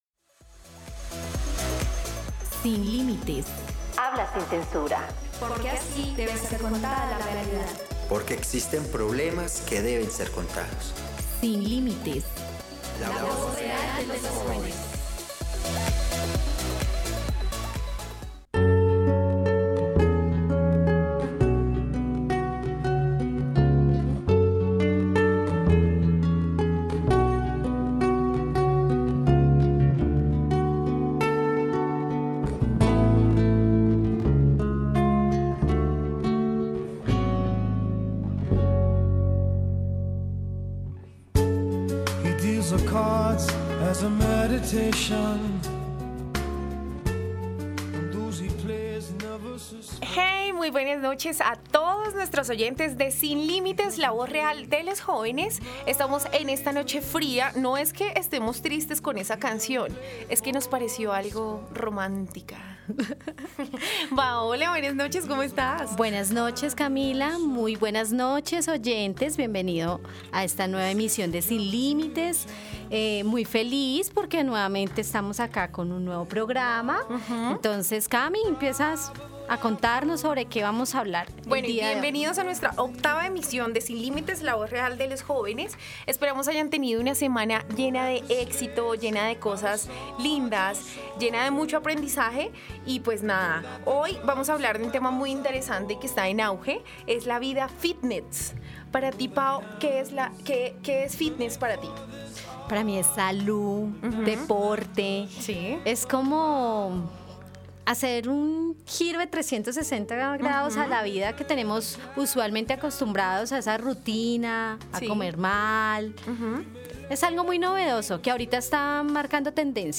¡Bienvenidos a Sin Límites, un programa radial diferente; en donde pensamos en ti!